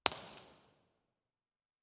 Room Impulse Response of a 9600 seat Auditorium
The medium Q source is a QSC K12.
File Type: XY Cardioid Stereo
Microphone: Core Sound Tetramic
Source: 14 sec Log Sweep
IR_TP2_QSC-K12_60ft.wav